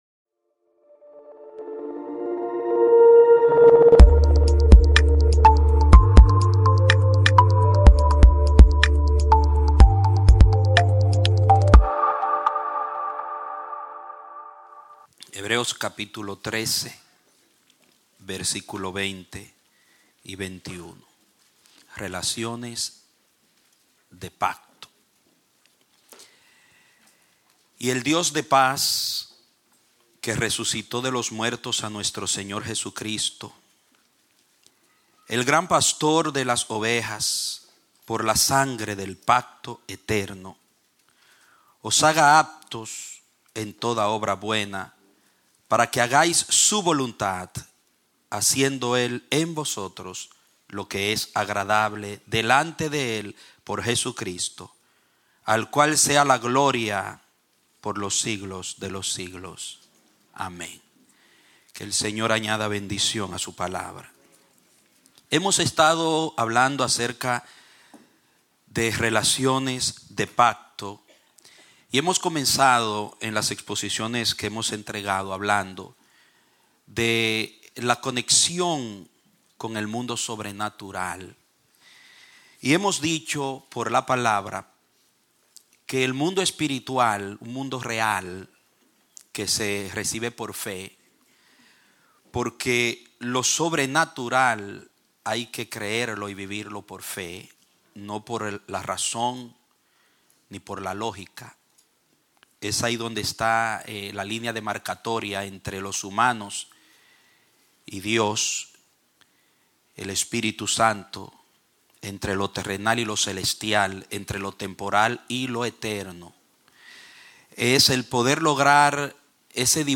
Un mensaje de la serie "Mensajes."